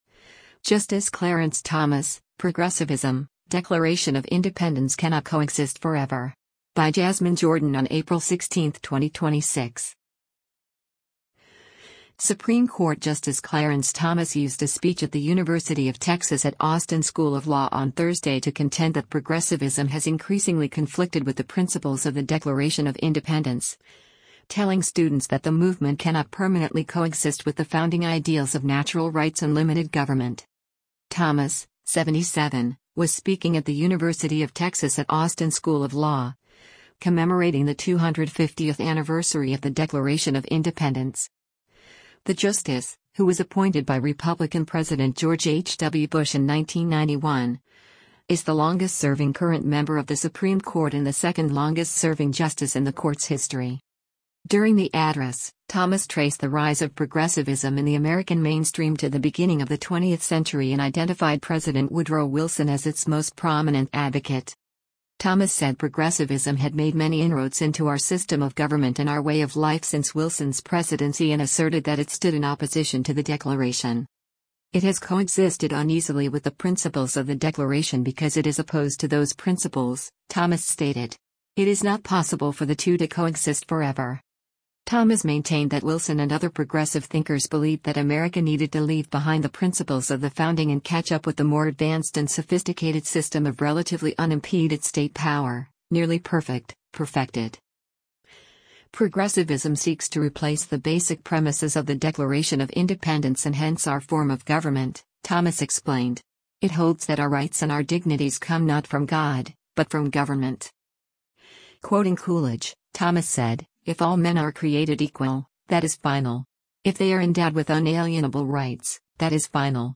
Supreme Court Justice Clarence Thomas used a speech at the University of Texas at Austin School of Law on Thursday to contend that progressivism has increasingly conflicted with the principles of the Declaration of Independence, telling students that the movement cannot permanently coexist with the founding ideals of natural rights and limited government.